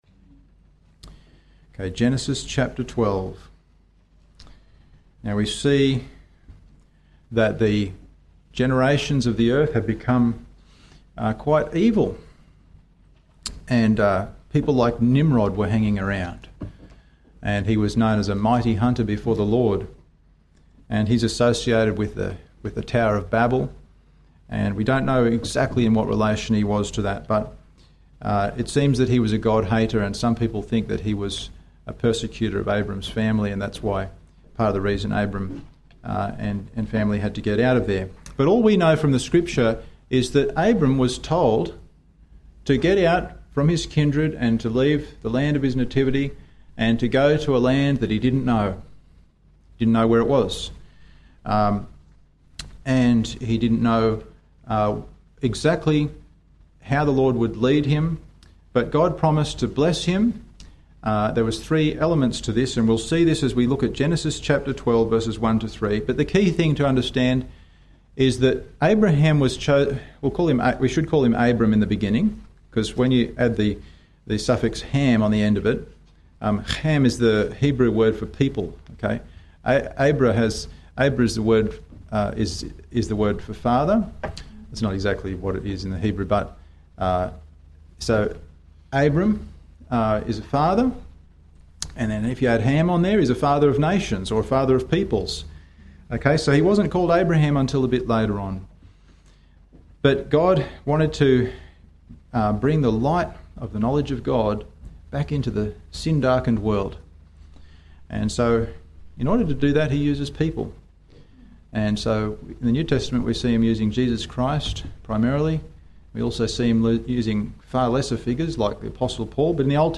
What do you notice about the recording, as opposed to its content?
Passage: Luke 16, Exodus 3 & 4 Service Type: Sunday Evening